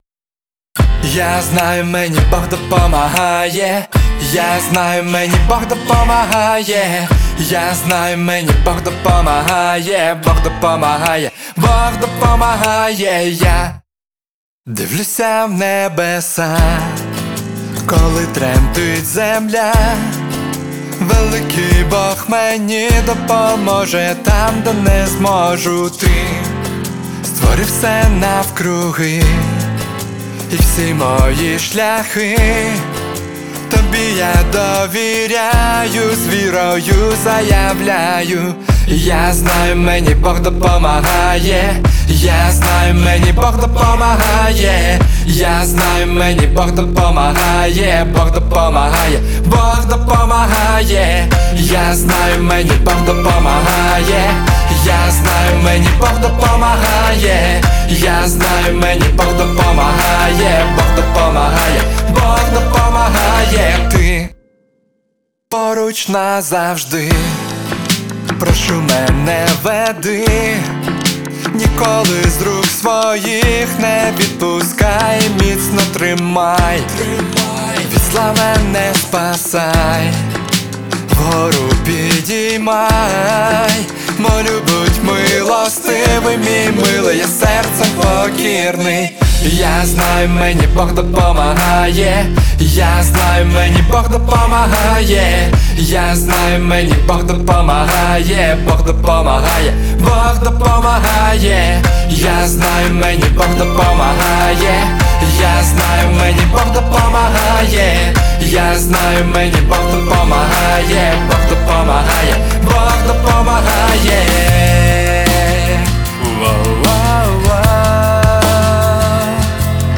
138 просмотров 116 прослушиваний 13 скачиваний BPM: 76